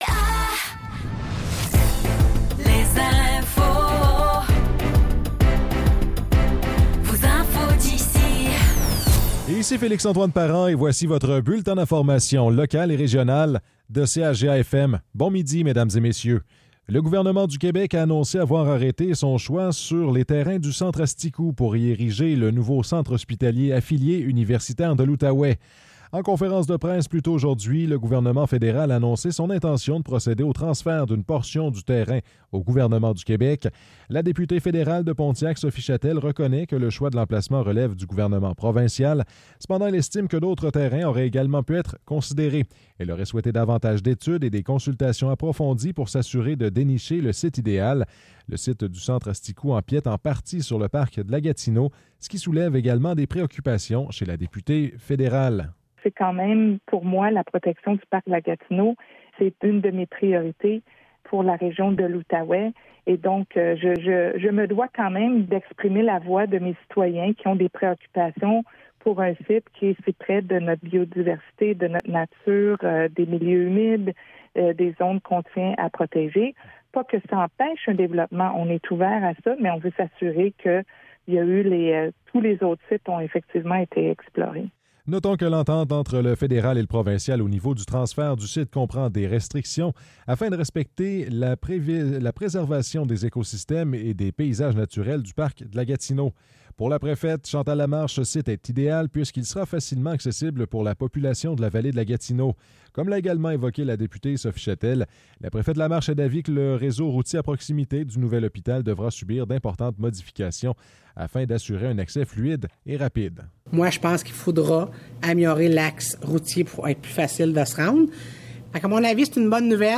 Nouvelles locales - 20 juin 2024 - 12 h